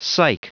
Prononciation du mot psych en anglais (fichier audio)
Prononciation du mot : psych